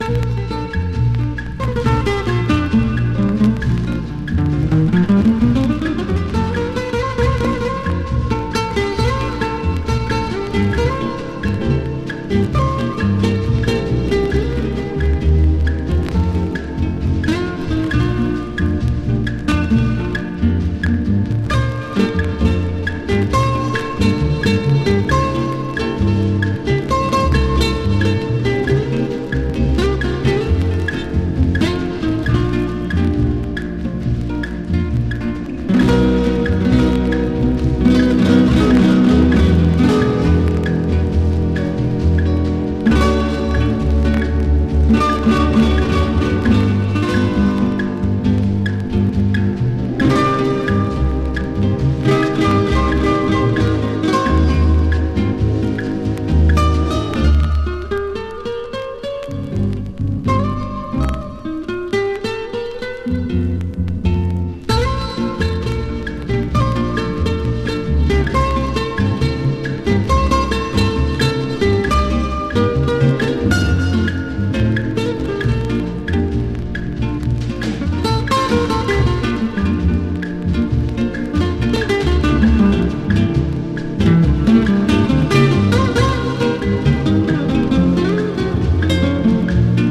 CHRISTMAS / GUITAR / TWIST / HOT ROD / SURF / SKA